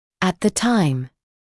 [æt ðə taɪm][эт зэ тайм]во время